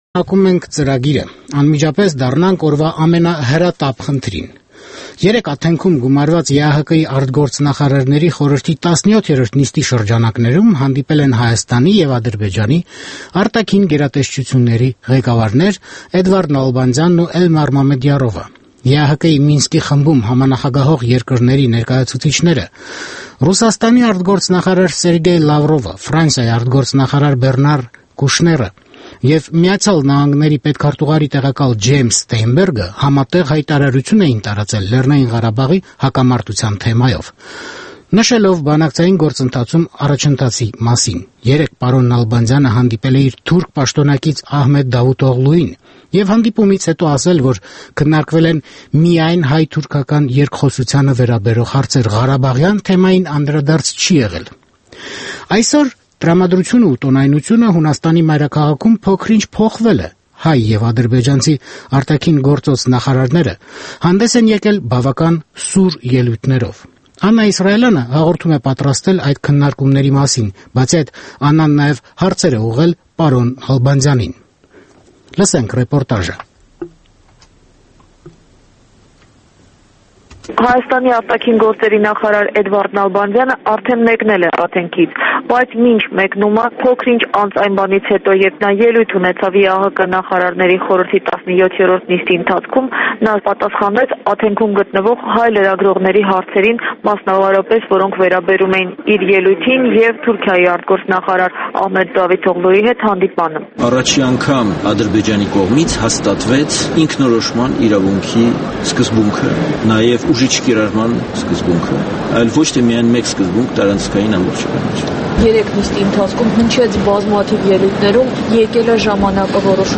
Ռեպորտաժ Աթենքից